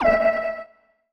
sci-fi_radar_ui_scan_02.wav